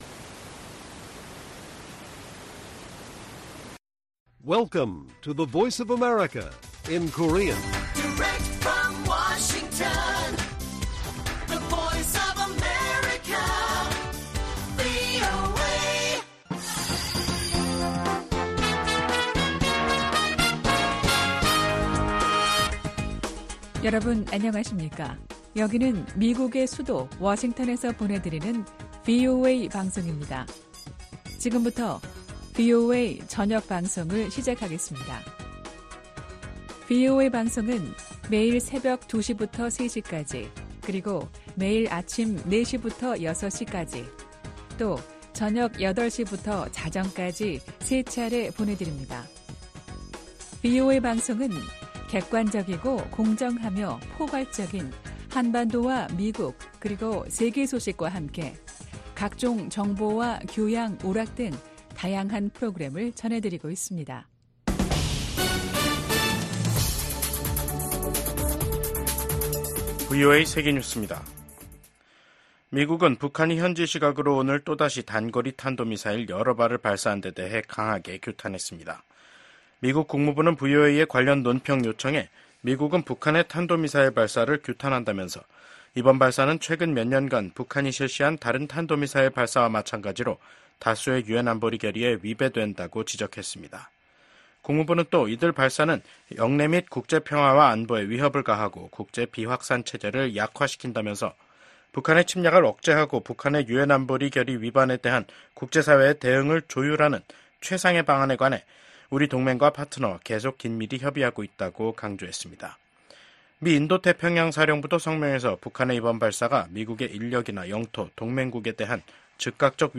VOA 한국어 간판 뉴스 프로그램 '뉴스 투데이', 2024년 9월 18일 1부 방송입니다. 북한이 한반도 시각 18일 탄도미사일 여러 발을 발사했습니다. 미국은 이번 발사가 다수의 유엔 안보리 결의 위반이라며 규탄했습니다. 한미연합사령관 지명자가 북한의 핵과 미사일 역량 진전을 최대 도전 과제로 지목했습니다.